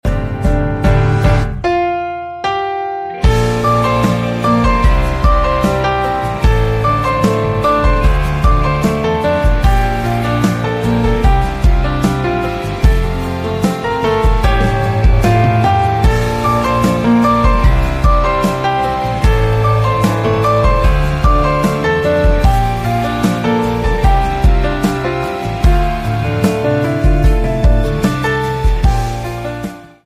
PVC Double Exit Pipe Extrusion sound effects free download
PVC Double Exit Pipe Extrusion Line, PVC Pipe Extrusion Machinery, with Conical Twin-screw Extruder